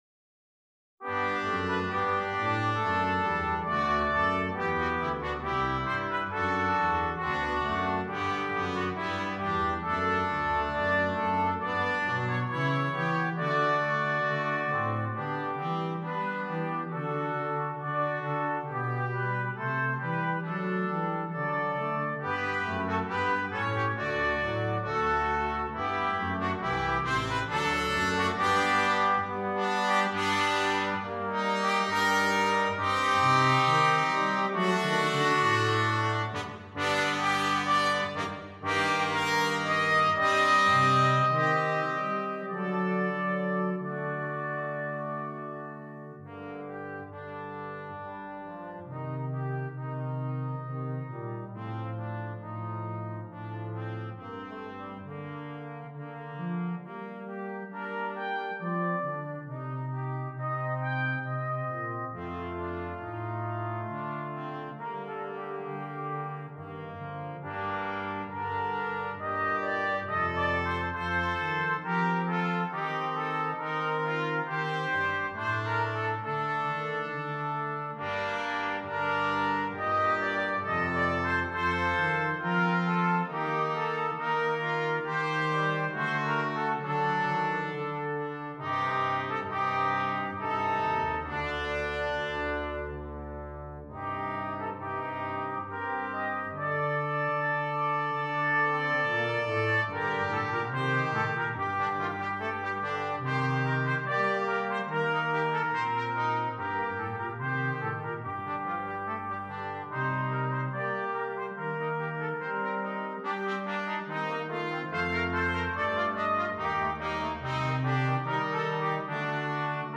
It is a Brass Quartet - a procession ... ancient warriors marching down the main thorough fare ... up front the able bodied .... followed by the ...